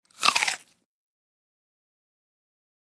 AppleCrunchBite PE677801
DINING - KITCHENS & EATING APPLE: INT: Single crunchy bite into an apple.